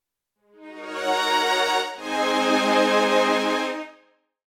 Identité sonore